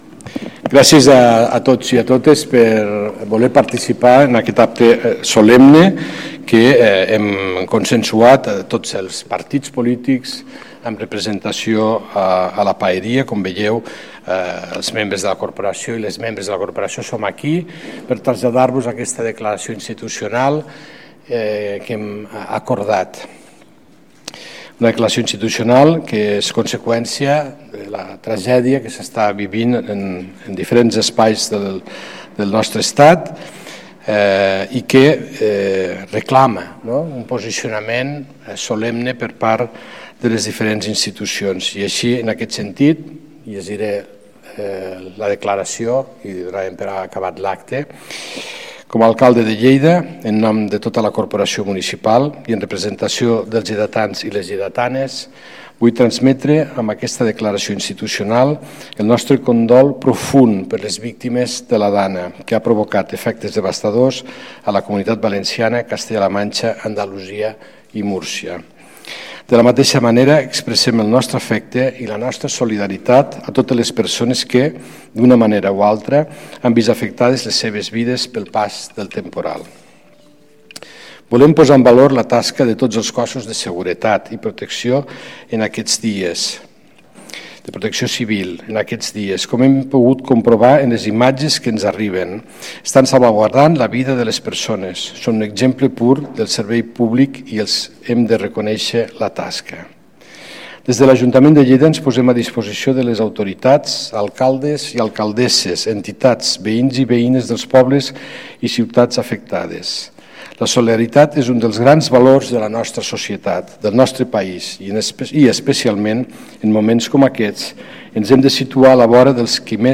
El paer en cap, Fèlix Larrosa, ha llegit la declaració institucional de l’Ajuntament de Lleida en solidaritat amb les víctimes i els municipis afectats per la DANA.